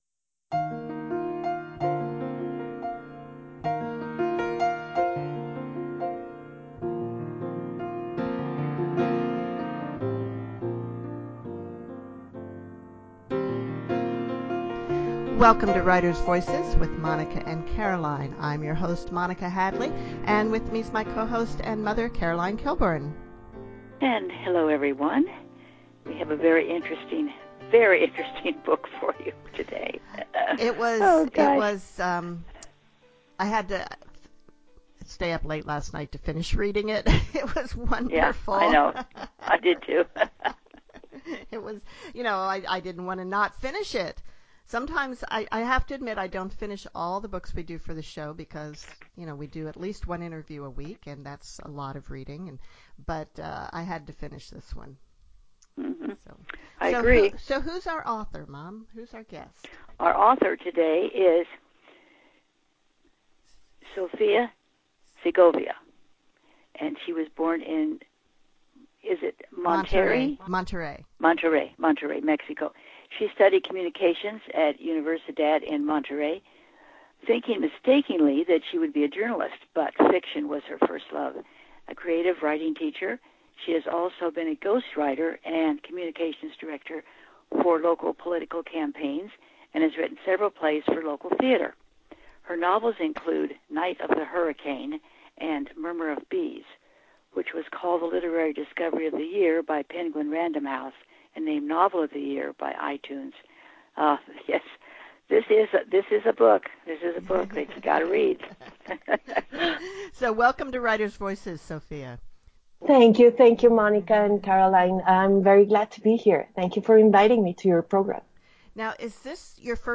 So begins our interview with Sofia Segovia, creator of The Murmur of Bees, a novel of historical fiction and magical realism.